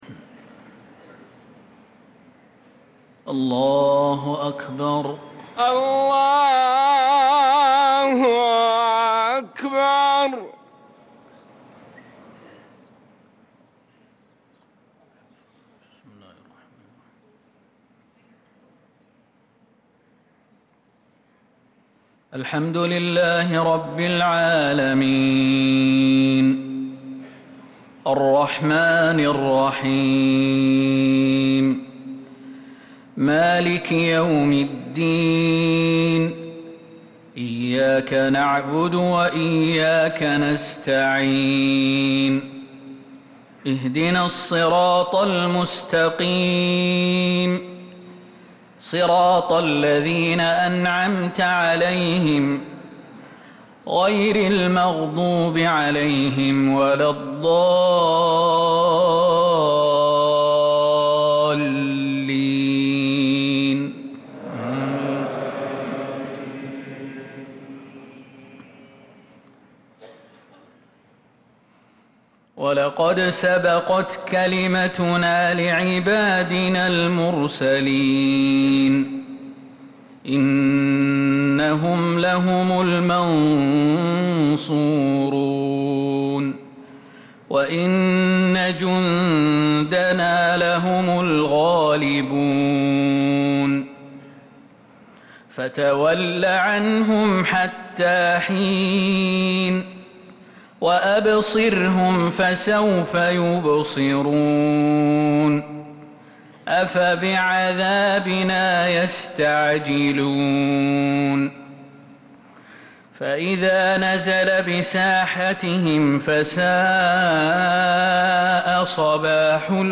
مغرب السبت 10 محرم 1447هـ من سورتي الصافات 171-182 و الدخان 30-37 | Maghrib prayer from Surat As-Saaffaat and Ad-Dukhan 5-7-2025 > 1447 🕌 > الفروض - تلاوات الحرمين